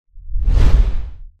Шорох скользящего звука